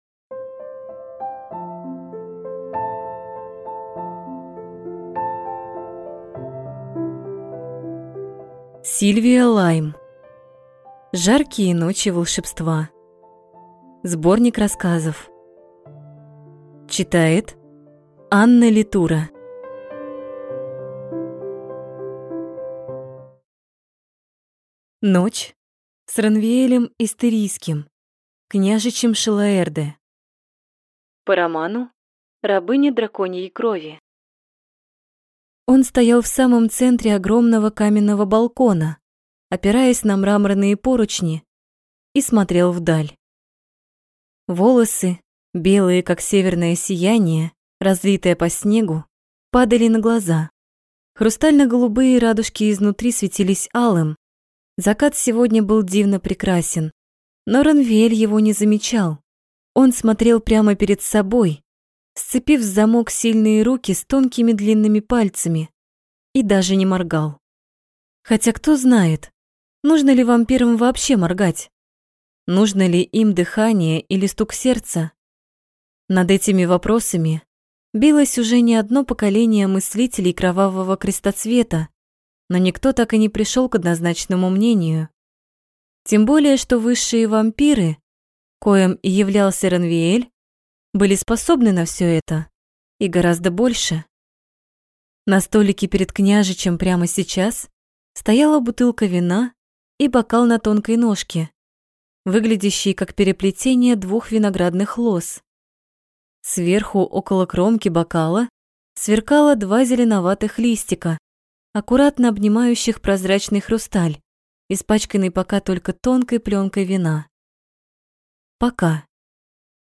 Аудиокнига Жаркие ночи волшебства | Библиотека аудиокниг